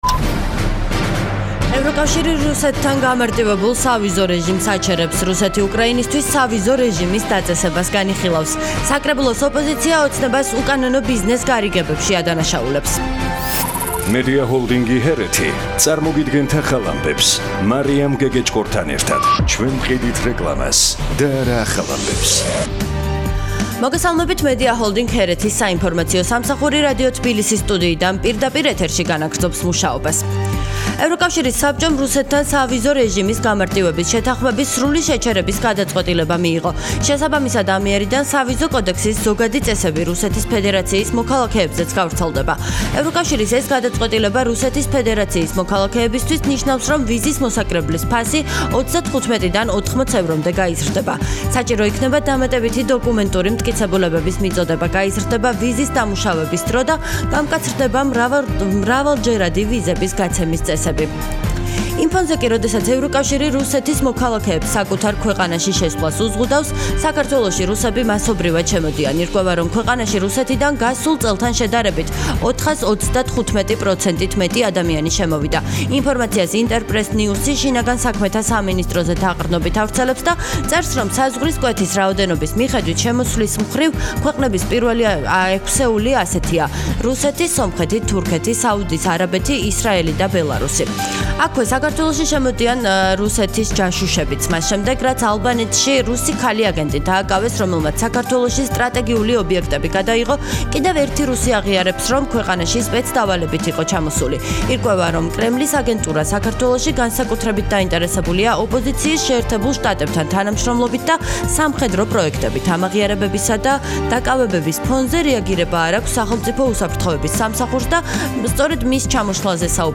ახალი ამბები 15:00 საათზე - HeretiFM